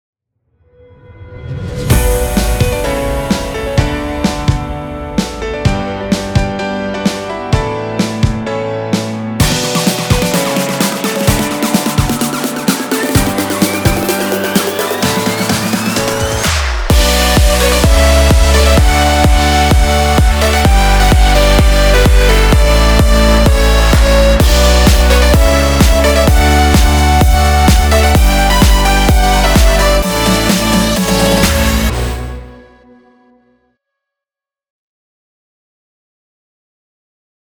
“ Progressive House声音，预设和和弦进行的下一个高端集合。”
DRUM LOOPS
• Brass Lead Loops
• Piano Chord Progressions
• Strings Buildups
• Synth Chords+Leads
• Vocal Atmospheres